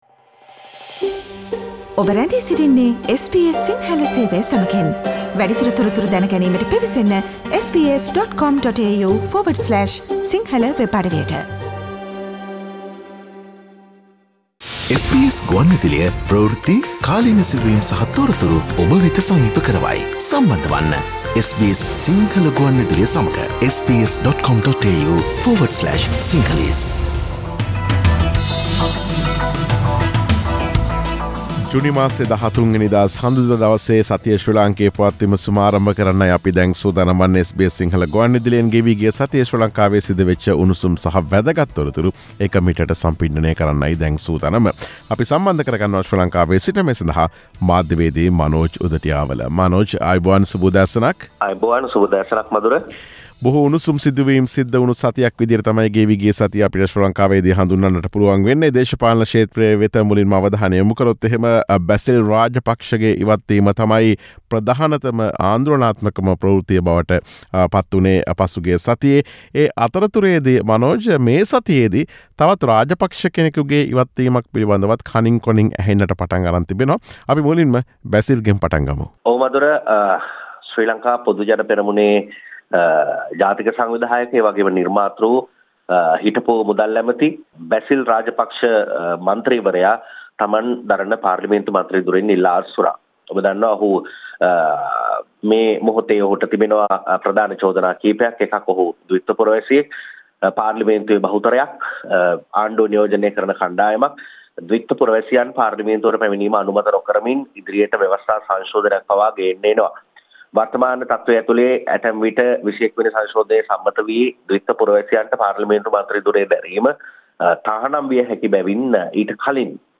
SBS සිංහල සේවය සෑම සඳුදා දිනකම ඔබ වෙත ගෙන එන සතියේ ශ්‍රී ලාංකීය පුවත් සමාලෝචනයට